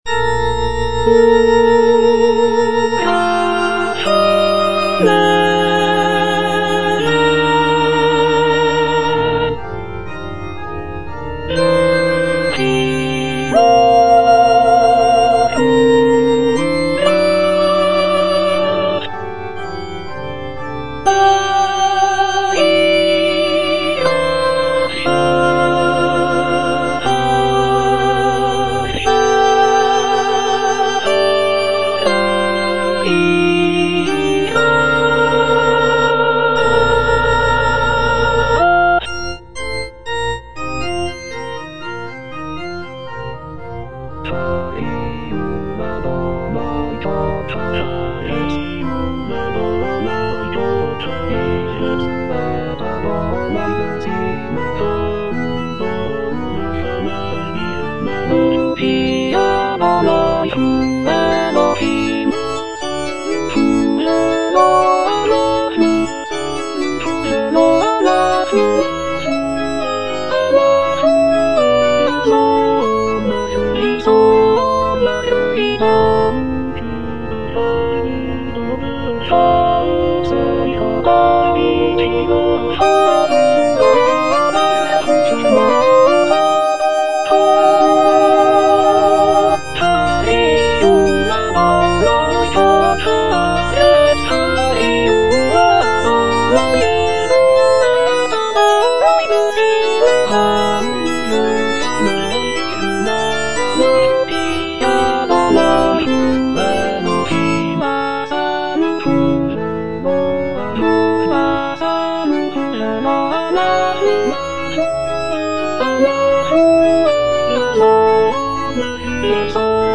(alto II) (Emphasised voice and other voices) Ads stop